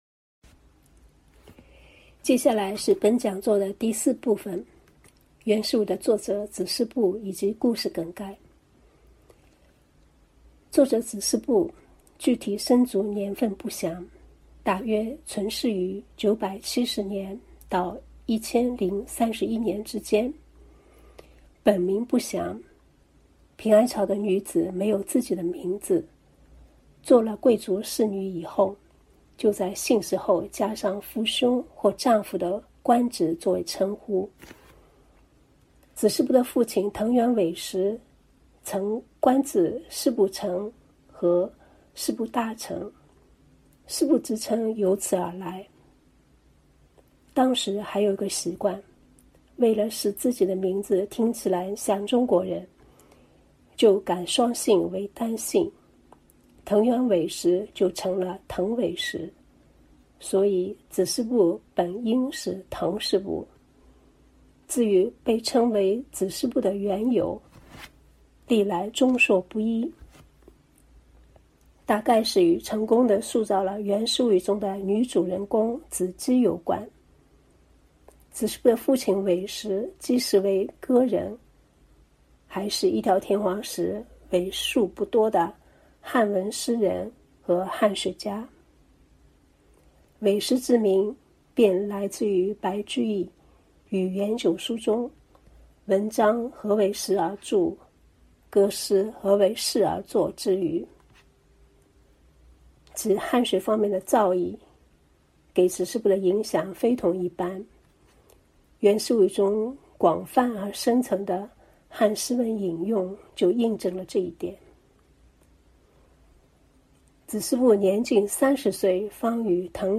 日本研究之窗——系列讲座（文学）